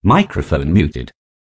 microphone.muted.wav